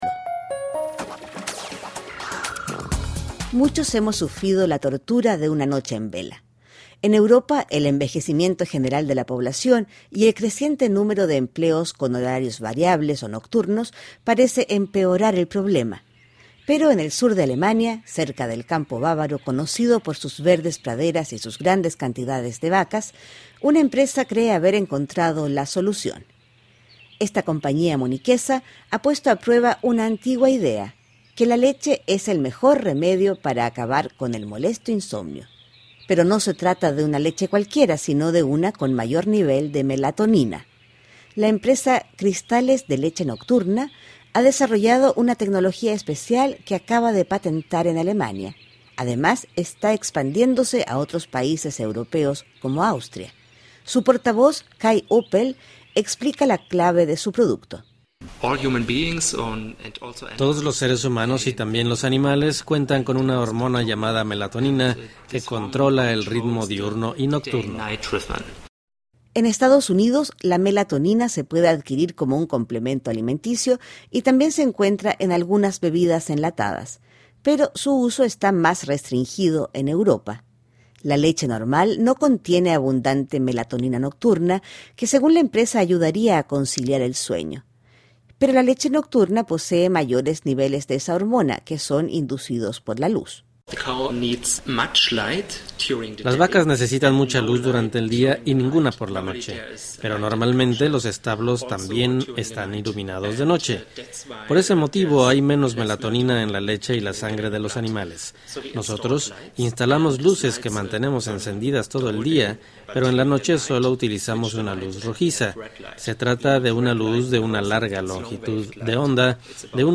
Una empresa alemana asegura haber encontrado la cura para el insomnio. El secreto: ingerir leche de vaca con mayores niveles de melatonina. Escuche el informe de la Deutsche Welle.